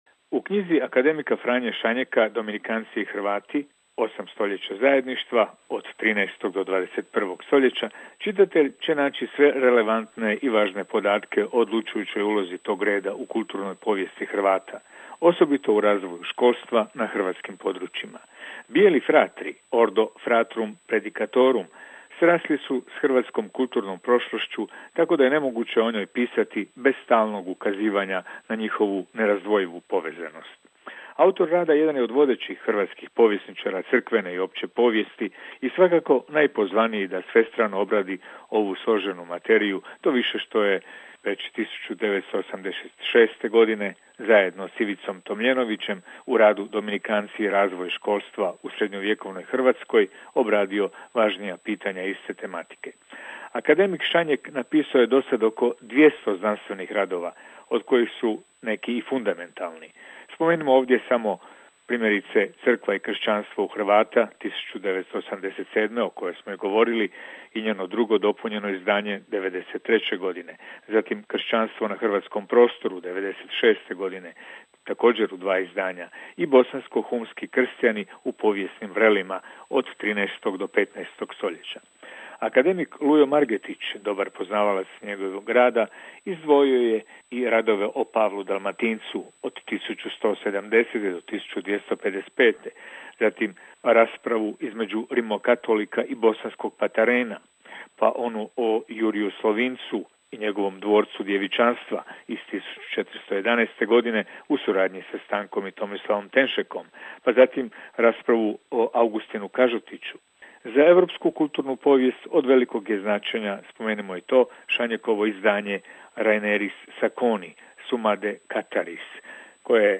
Recenzija knjige